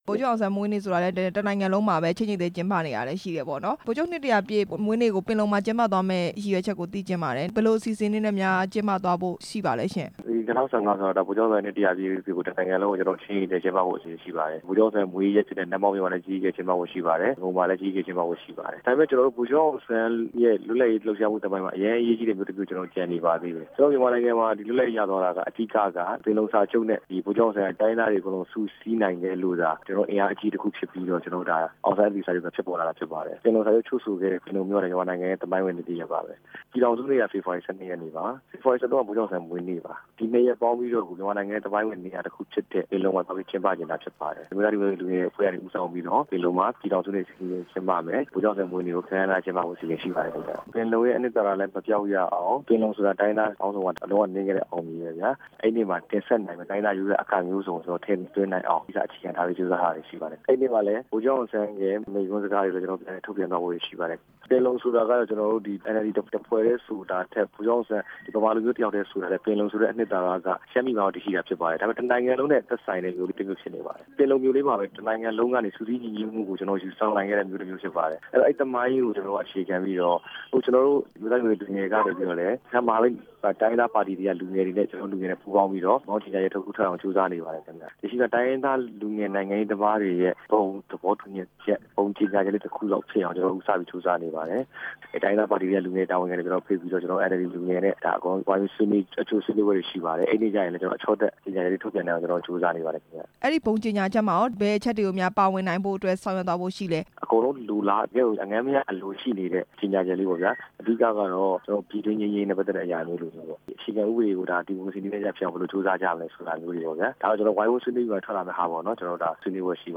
ပင်လုံမြို့မှာ ဗိုလ်ချုပ်ရာပြည့်ပွဲ ကျင်းပဖို့ ပြင်ဆင်နေတဲ့ အကြောင်း မေးမြန်းချက်